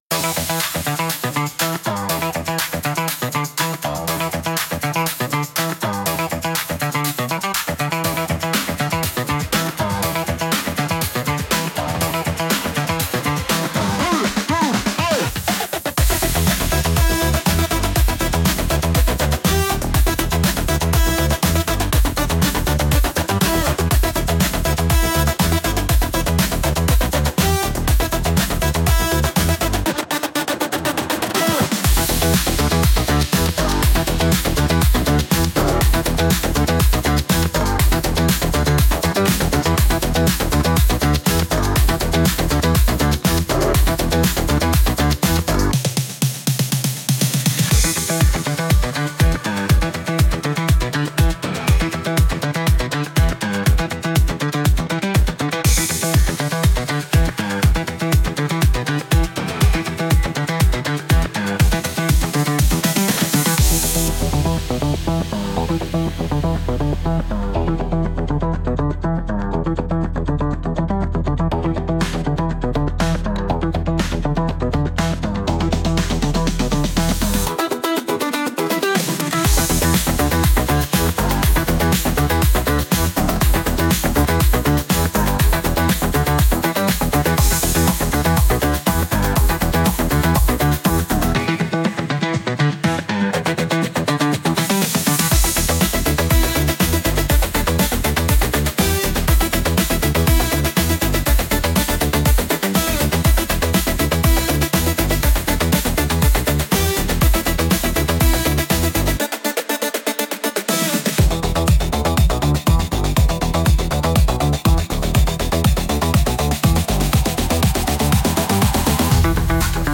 集合時間に遅れそうなときのBGM